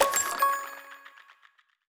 Special & Powerup (14).wav